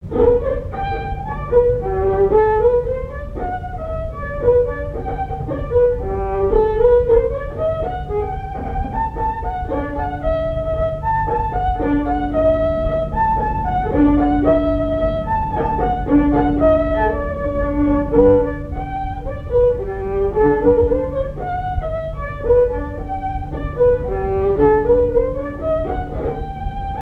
Chants brefs - A danser
danse : polka
Airs à danser aux violons et deux chansons
Pièce musicale inédite